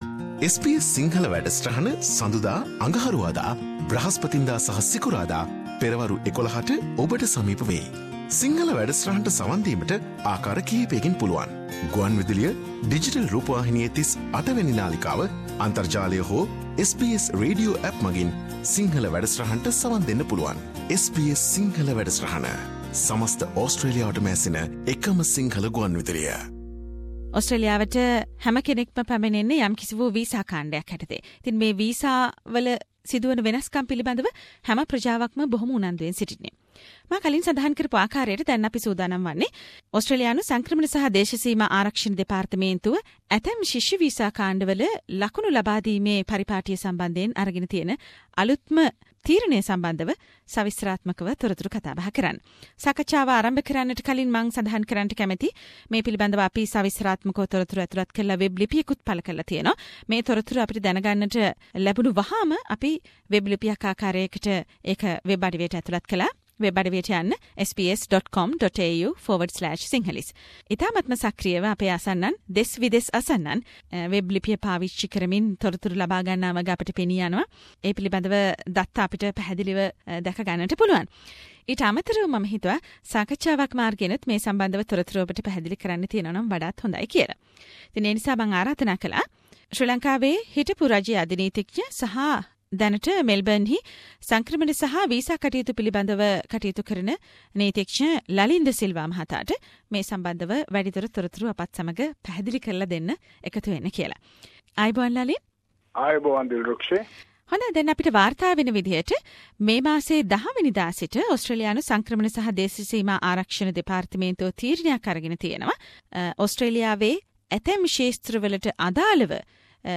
මේ අප ඒ සම්බන්දව ඔබ වෙත ගෙන එන සාකච්චාවක්....